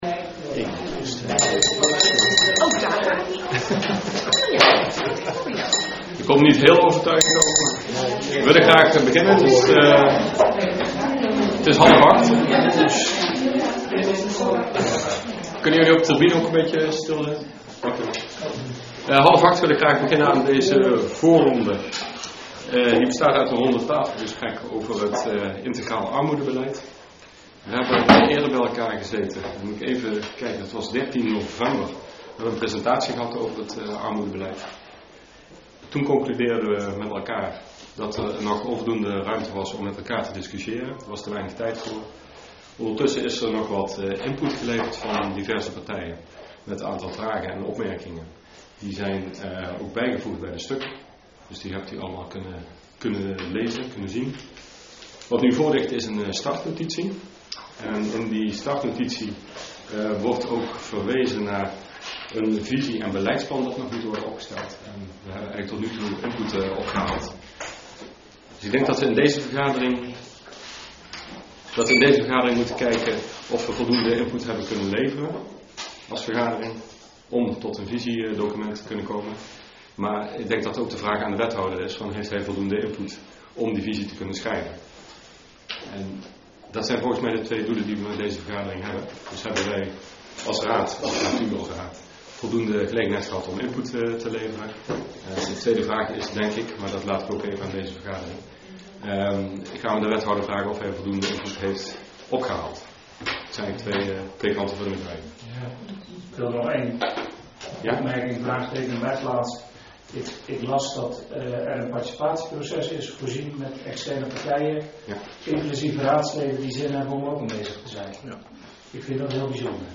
Rondetafelgesprek "Integraal Armoedebeleid"